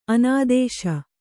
♪ anādēśa